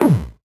CS_VocoBitA_Hit-13.wav